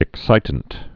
(ĭk-sītnt)